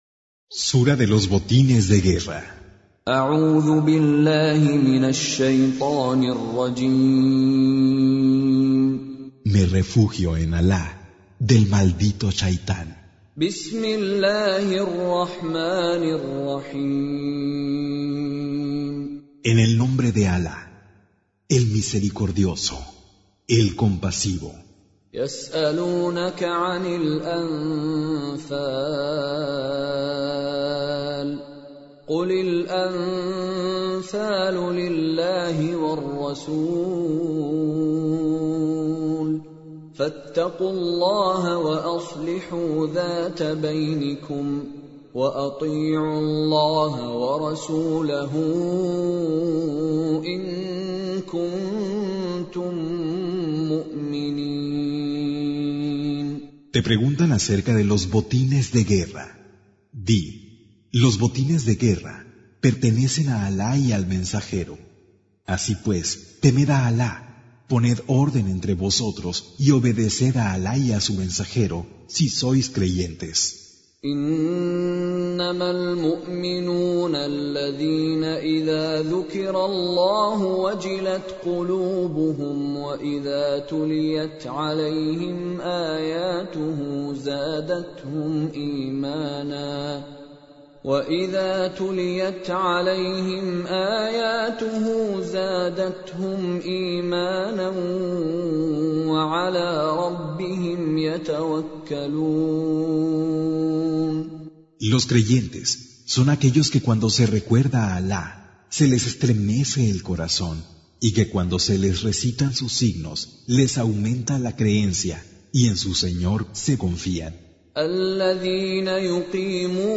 Traducción al español del Sagrado Corán - Con Reciter Mishary Alafasi
Surah Repeating تكرار السورة Download Surah حمّل السورة Reciting Mutarjamah Translation Audio for 8. Surah Al-Anf�l سورة الأنفال N.B *Surah Includes Al-Basmalah Reciters Sequents تتابع التلاوات Reciters Repeats تكرار التلاوات